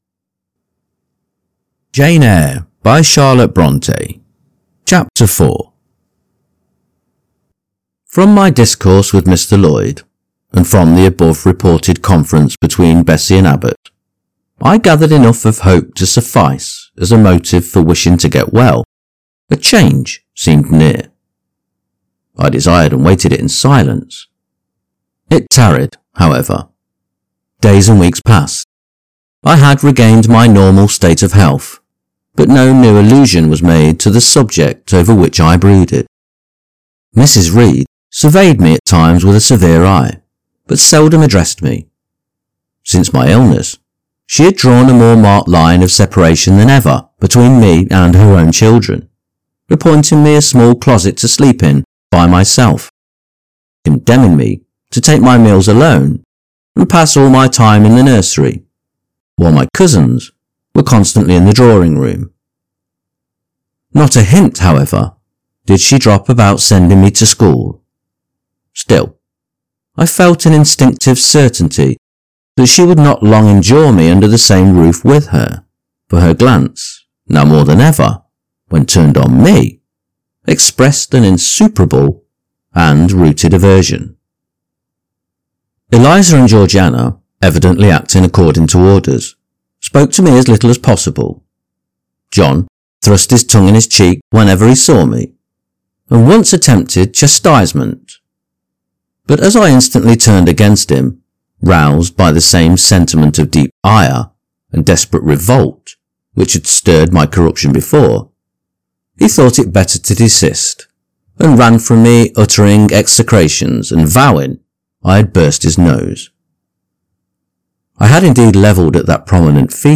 Jane Eyre – Charlotte Bronte – Chapter 4 | Narrated in English - Dynamic Daydreaming